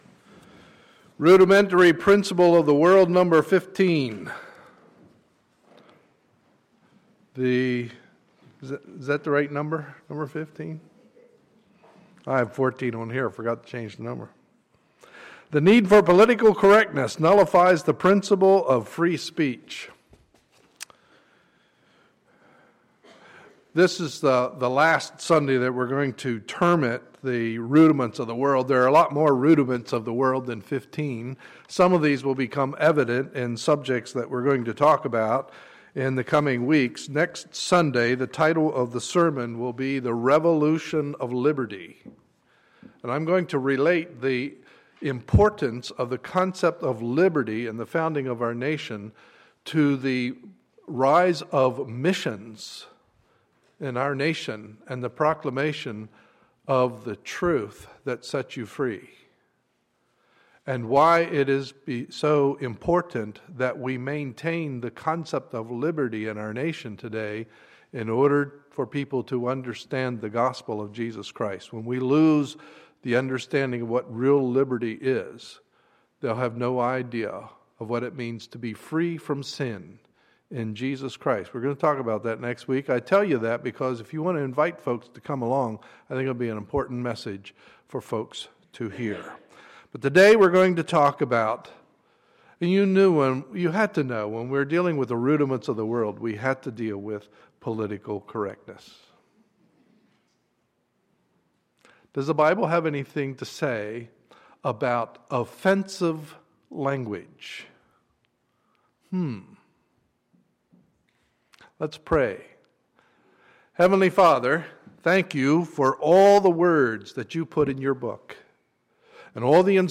Sunday, June 22, 2014 – Morning Service